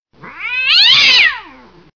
• Animal Ringtones